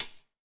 Index of /90_sSampleCDs/EdgeSounds - Drum Mashines VOL-1/M1 DRUMS
MTAMBOURIN19.wav